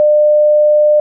tuuut-pygbag.ogg